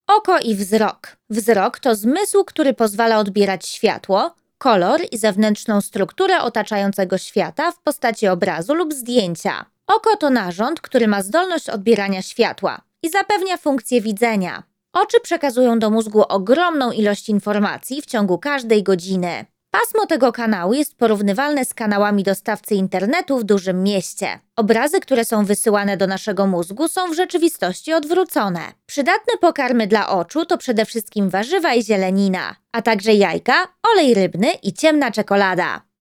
E-learning
YoungProfessionalFriendlyWarmPersonableBrightEnergeticUpbeat
All our voice actors record in their professional broadcast-quality home studios using high-end microphones.